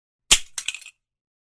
Звуки мышеловки
Мышеловка захлопнулась и резко подскочила